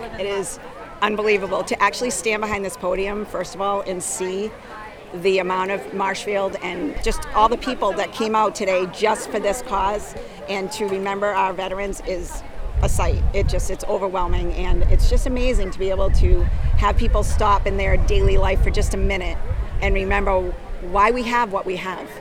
Hundreds of people lined the Veterans Memorial Park for the annual observance.